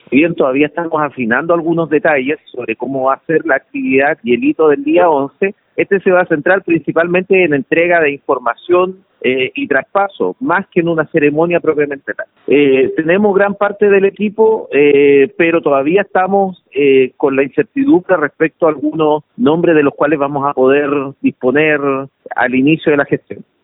Así lo informó el delegado entrante, Julio Anativia, quien confirmó que no habrá acto con invitados ni alfombra roja en las dependencias de la Delegación Presidencial, sino que se trata más bien de una reunión de trabajo.